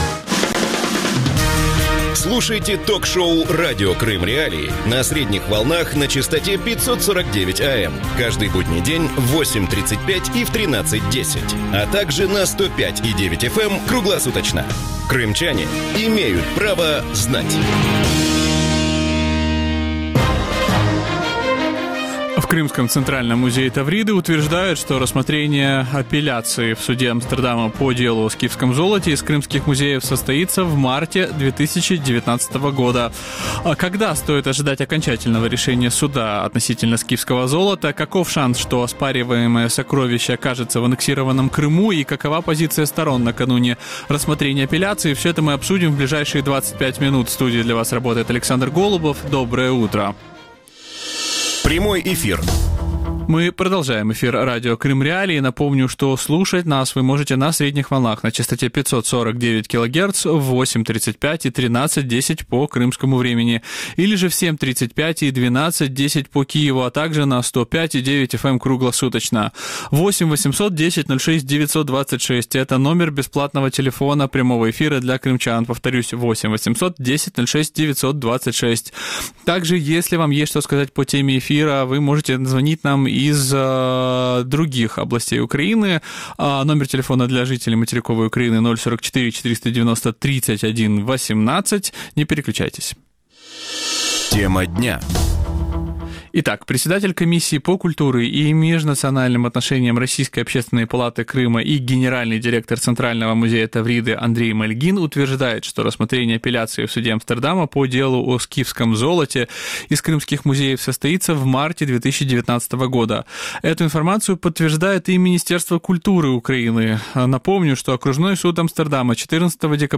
Когда стоит ожидать решения суда относительно «скифского золота»? Каков шанс, что оспариваемое сокровище окажется в аннексированном Крыму? И какова позиция сторон накануне рассмотрения апелляции? Гости эфира